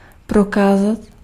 Ääntäminen
IPA: /de.mɔ̃t.ʁe/